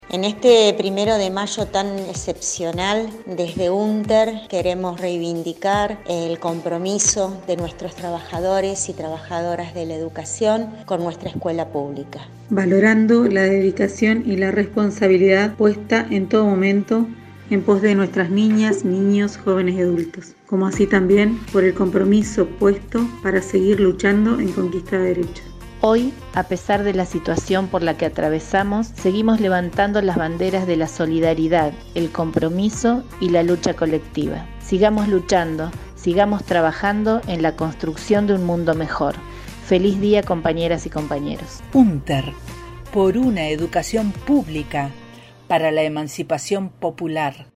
Saludo día internacional de lxs trabajadorxs, voces de Secretarixs Generalxs de Seccionales.
spot radios unter 1 de mayo.mp3